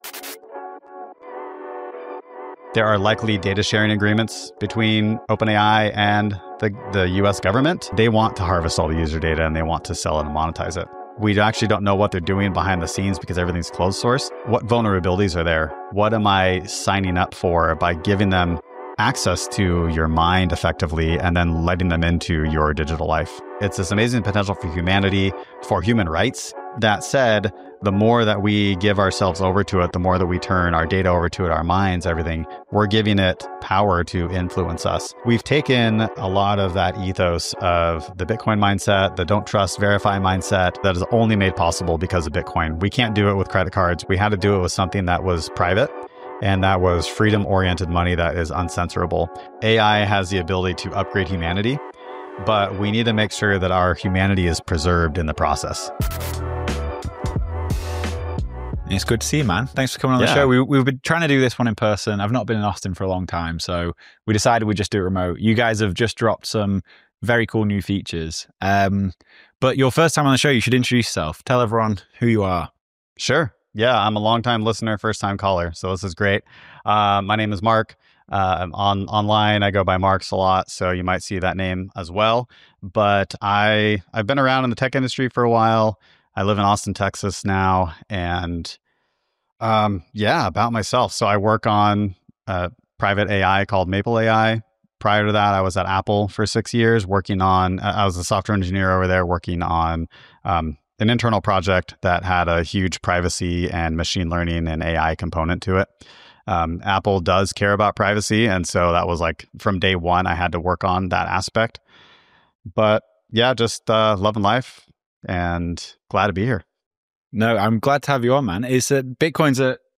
In this year-end Bits + Bips roundtable